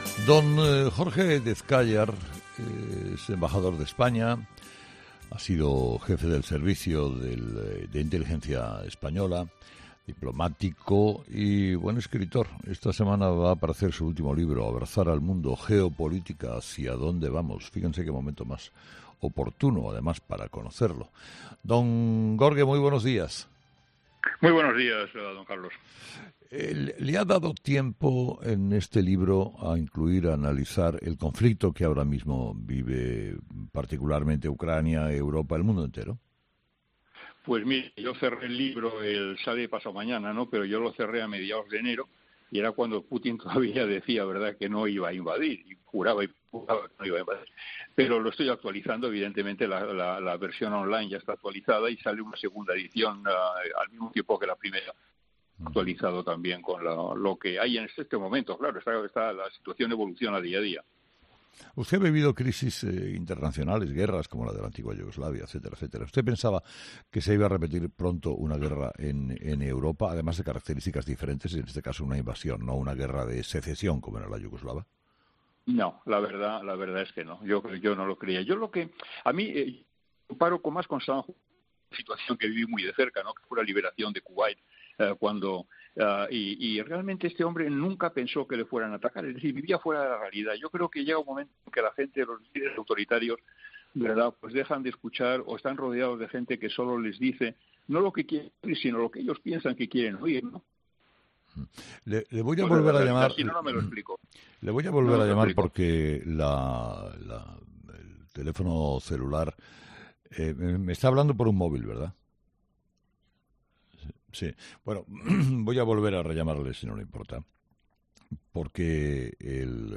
el espacio de humor